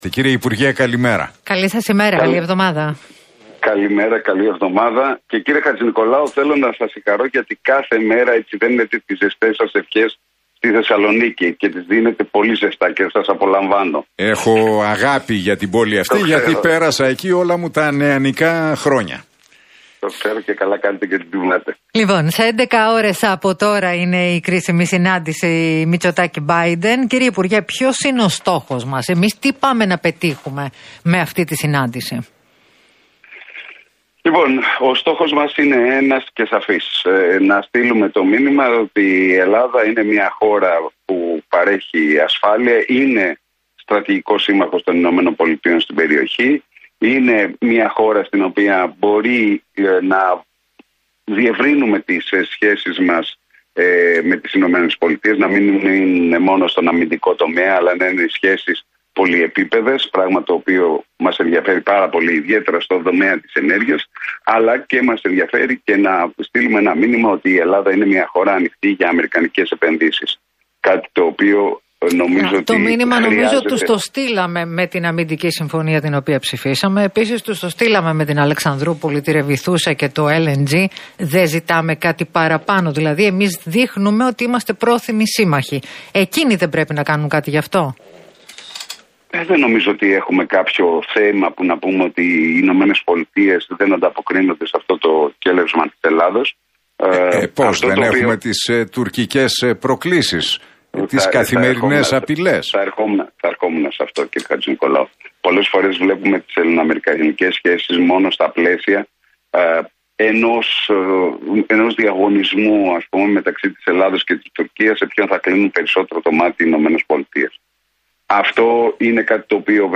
Ο αναπληρωτής υπουργός Εξωτερικών Μιλτιάδης Βαρβιτσιώτης σε συνέντευξη που παραχώρησε στο ραδιοφωνικό σταθμό Realfm 97,8